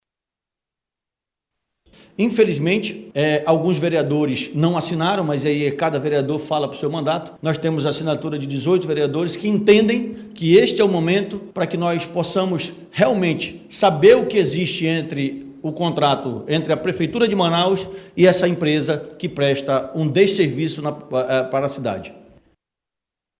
Sonora-Elissandro-Bessa-vereador.mp3